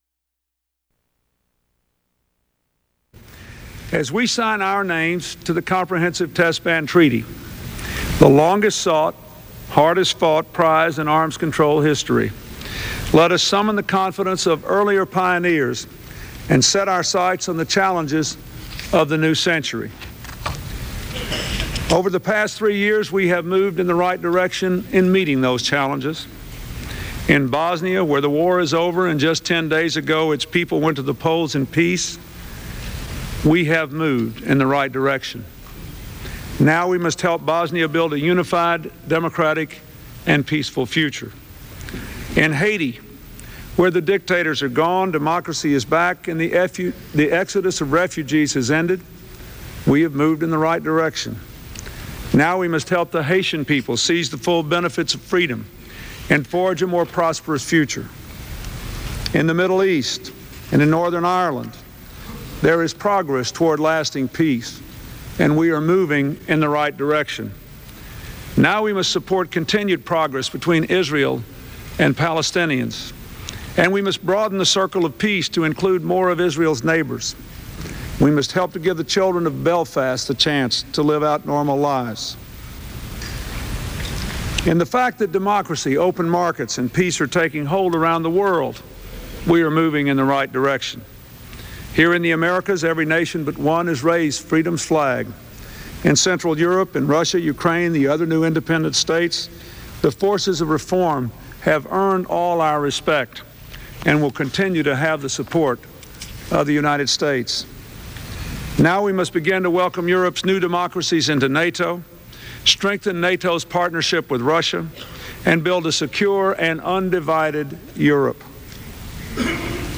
U.S. President Bill Clinton addresses the United Nations General Assembly on the occasion of signing the Nuclear Test Ban Treaty
Broadcast on CNN-TV, Sept. 24, 1996.